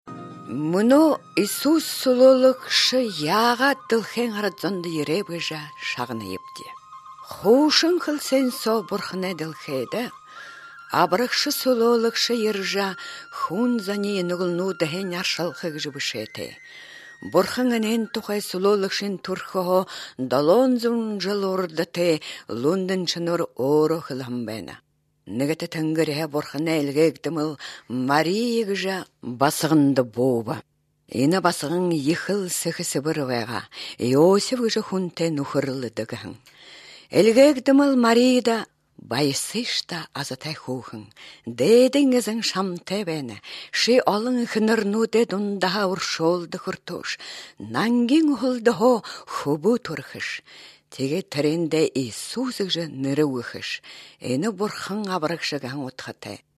18 December 2011 at 6:21 am Sounds like a Native American or Central Asian language, judging from the faint background music.